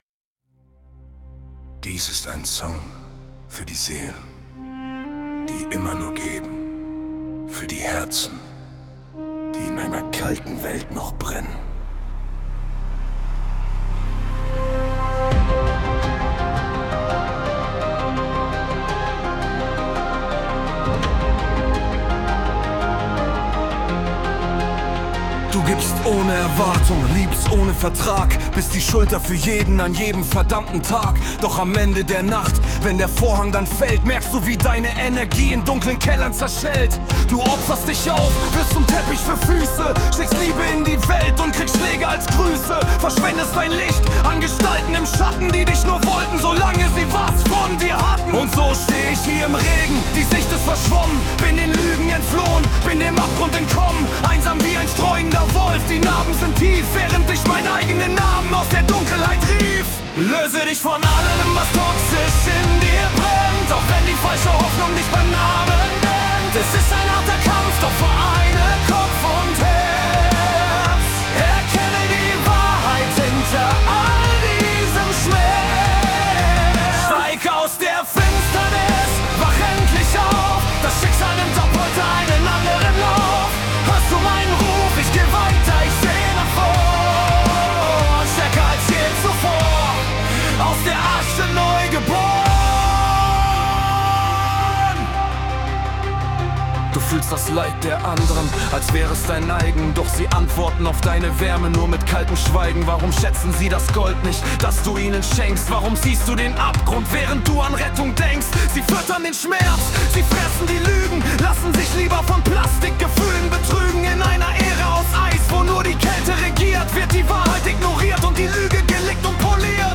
Pop, Epic, Sentimental